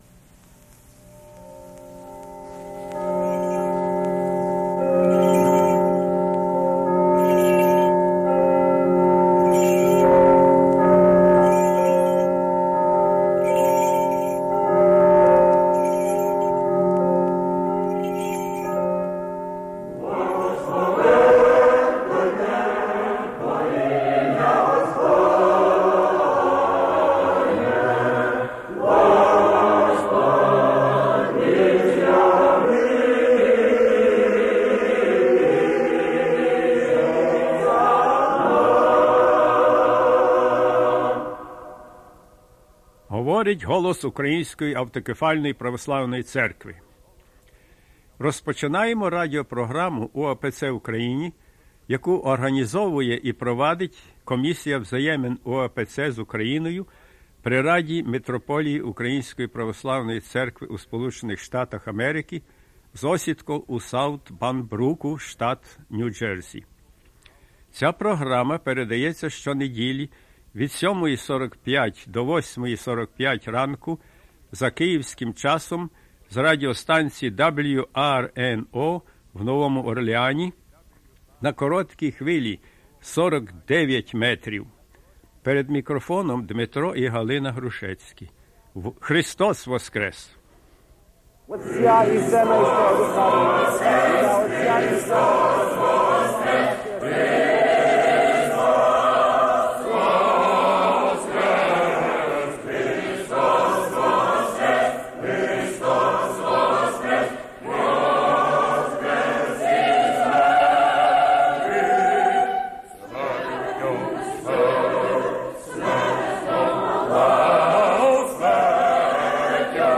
Gospel reading and "Spiritual Discussion"
Choral rendition